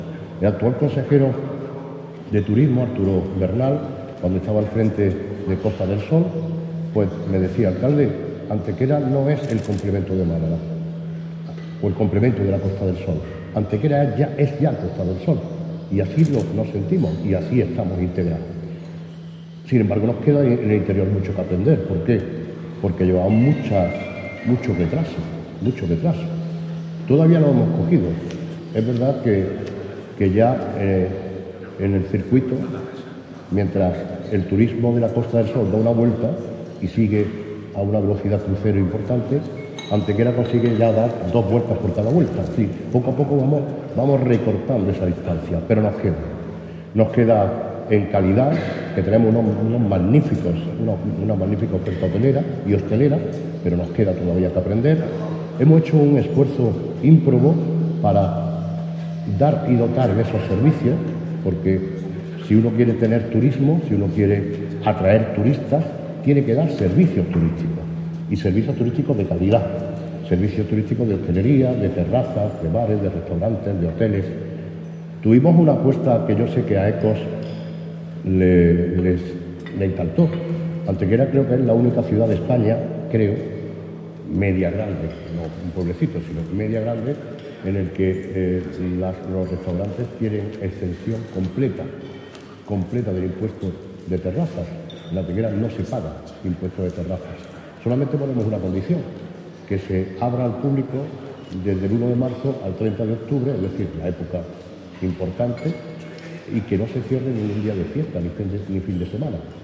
El Hotel Convento de la Magdalena de Antequera ha acogido en la mañana de hoy el desarrollo de la "Jornada de Turismo de Interior. Desafíos y Oportunidades", iniciativa que ha organizado conjuntamente la Asociación de Empresarios Hoteleros de la Costa del Sol (AEHCOS) y el periódico "Sur", contando para ello con el patrocinio de la Diputación de Málaga y Turismo Costa del Sol.
Cortes de voz